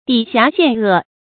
抵瑕陷厄 dǐ xiá xiàn è
抵瑕陷厄发音